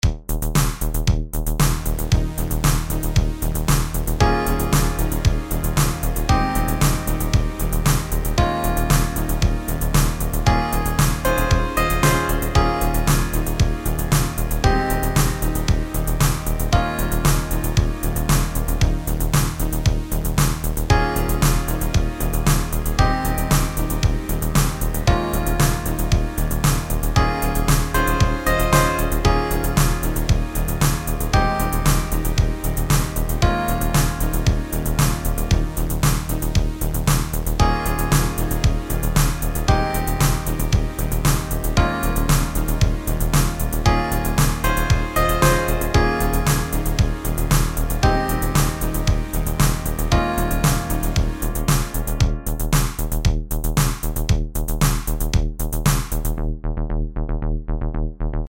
But this little track, has a feel that just calms you.